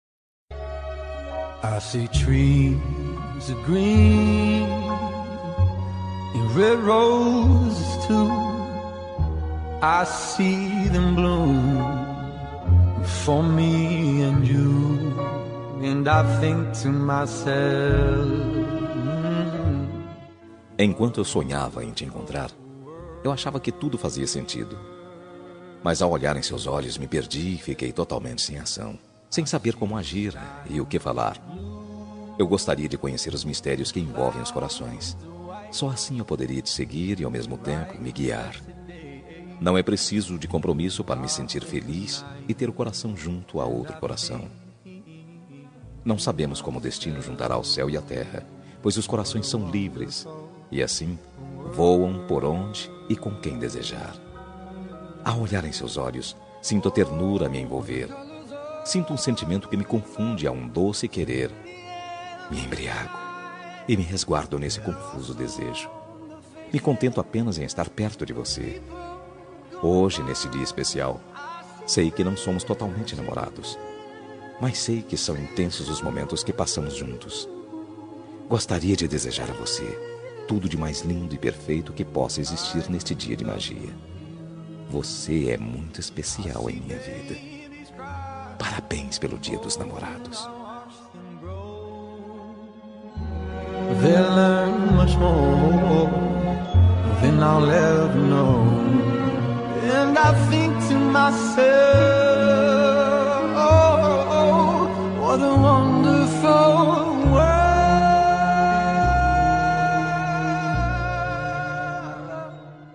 Dia dos Namorados – Para Namorada – Voz Masculina – Cód: 6873